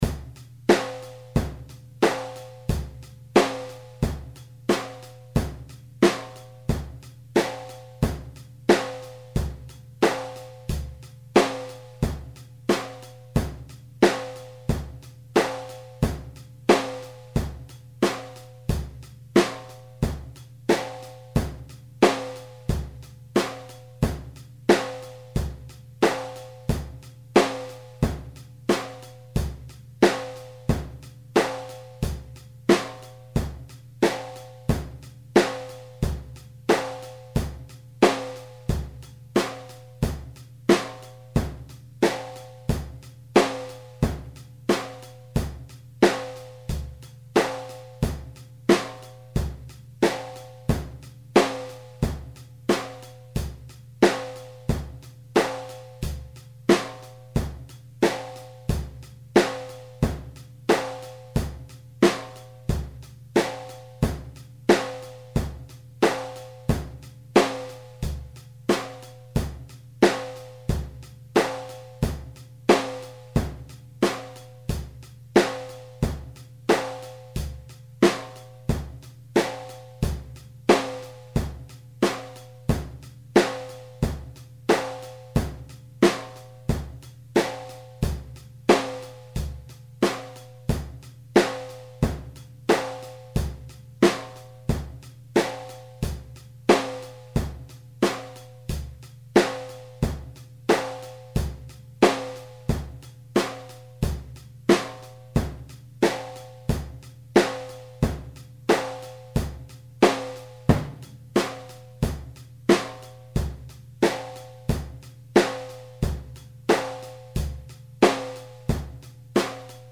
daß kein einziges soundfile wirklich tight gespielt ist. ich schäme mich für nix. das hier ist von einer maschine gespielt. und noch tighter gehts jetz nu echt nicht.
bummtak.mp3